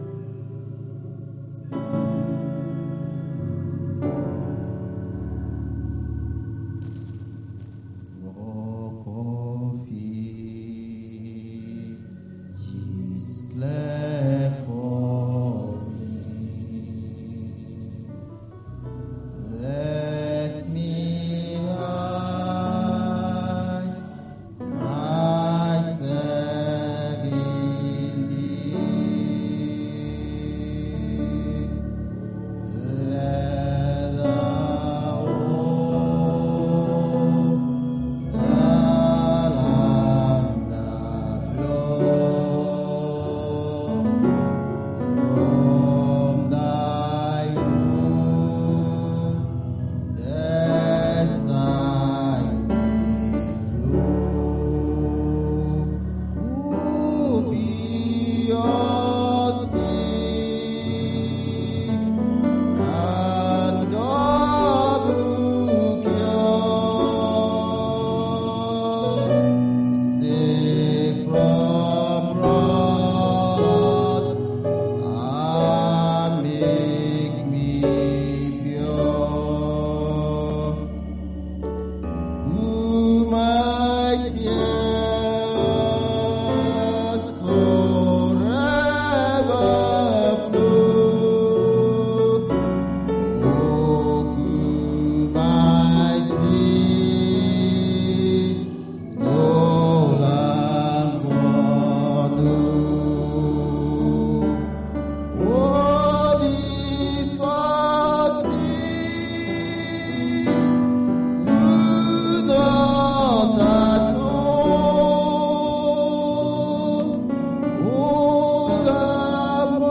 Wed. Prayer Meeting 09/10/24 - Evang.